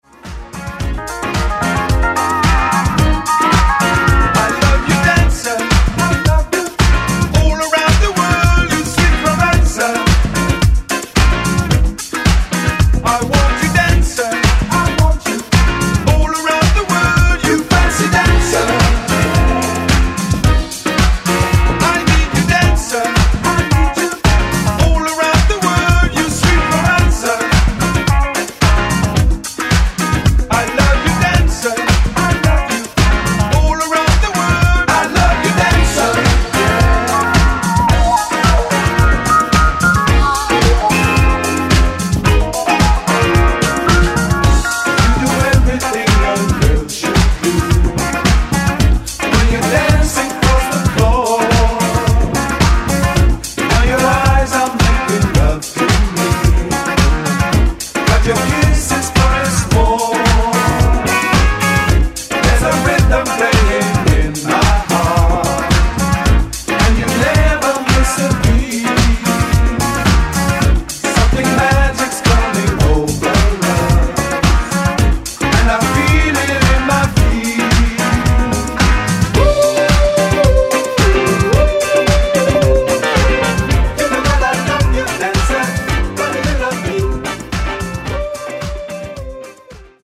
Genre: 80's
Clean BPM: 113 Time